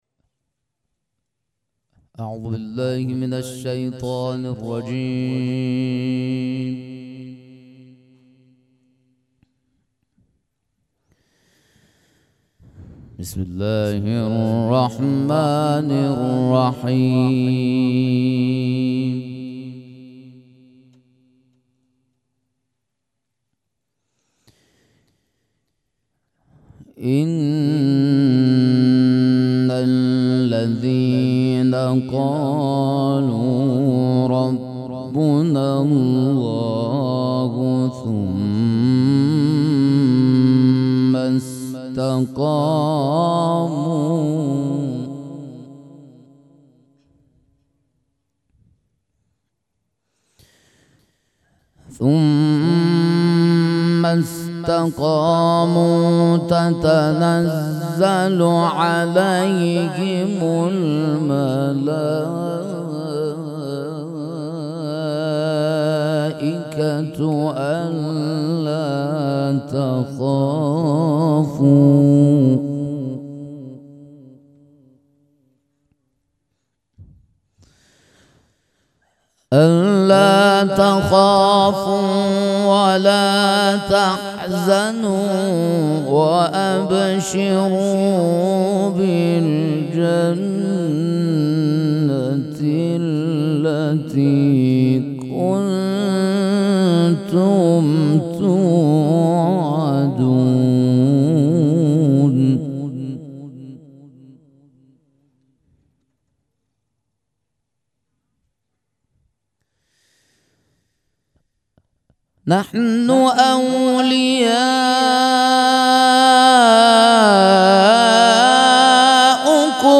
قرائت قرآن کریم
دانلود تصویر قرائت قرآن کریم favorite مراسم مناجات شب بیست و یکم ماه رمضان قاری
سبک اثــر قرائت قرآن